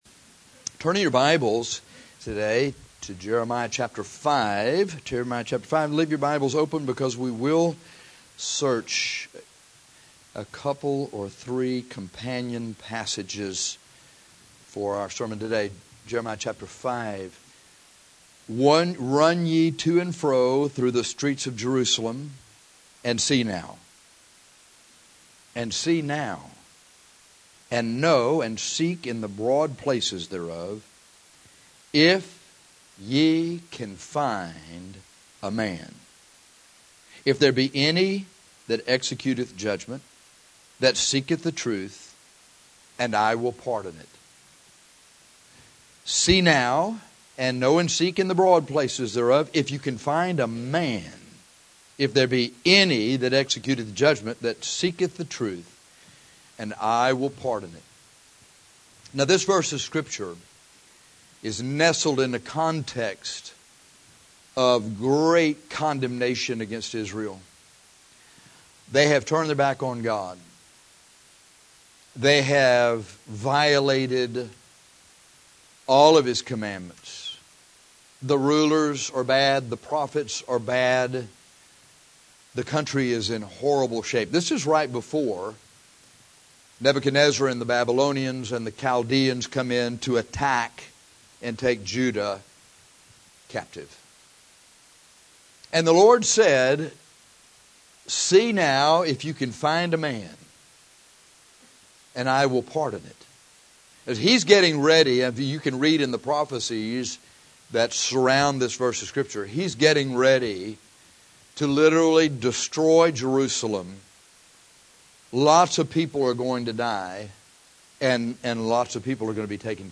But today we want to preach on men.